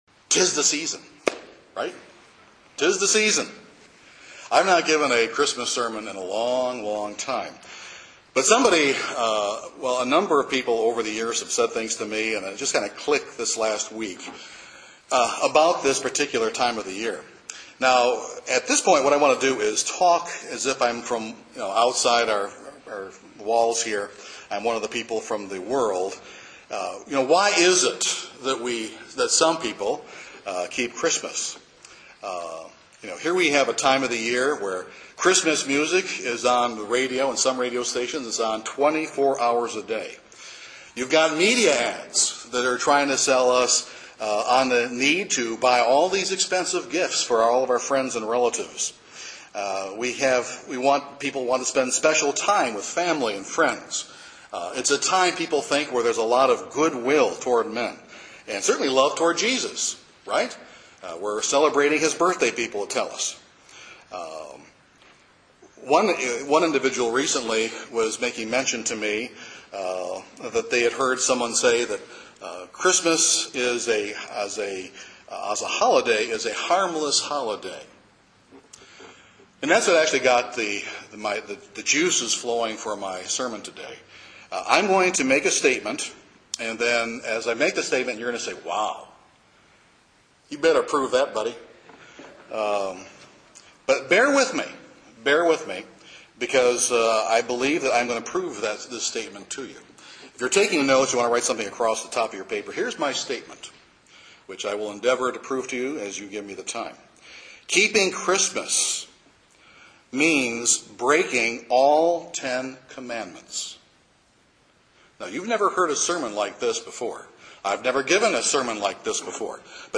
This sermon demonstrates that Christmas is anything but a harmless observance to honor Jesus Christ. In fact, it is an observance that dishonors Jesus Christ by breaking each of the Ten Commandments!